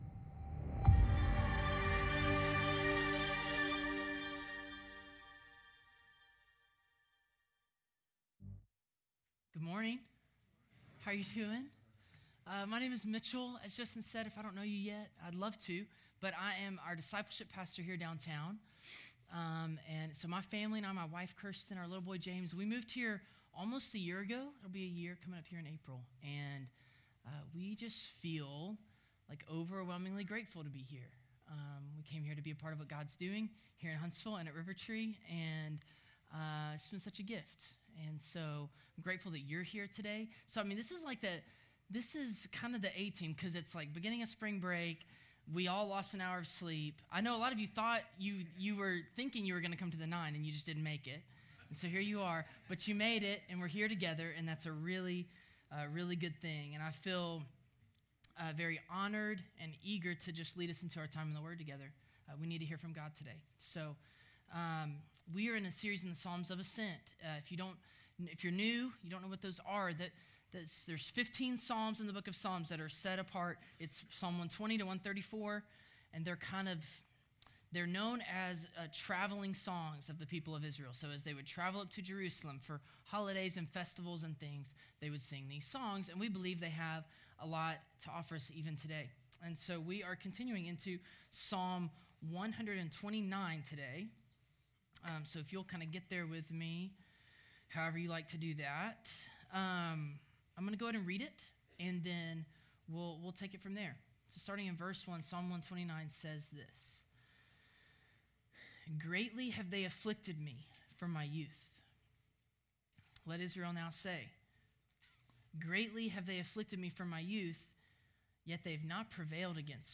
Sermon Notes Sermon Audio…